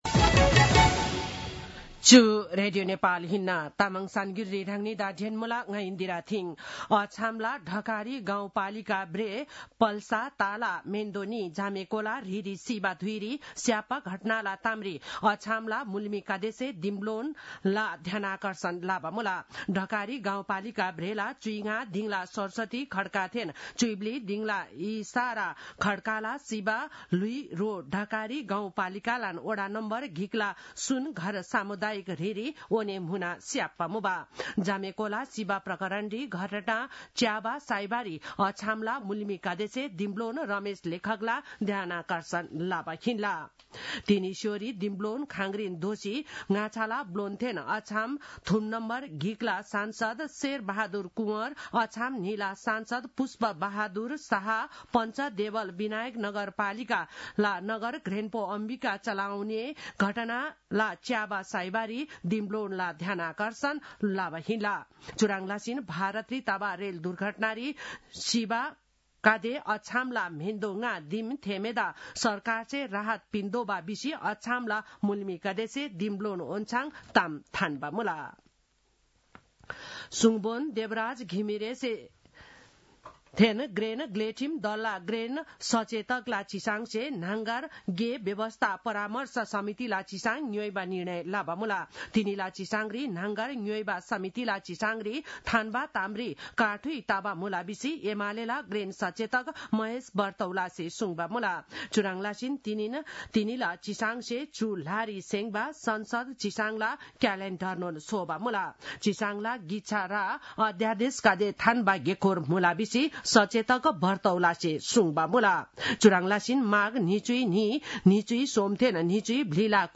तामाङ भाषाको समाचार : १५ माघ , २०८१
Tamang-news-10-14.mp3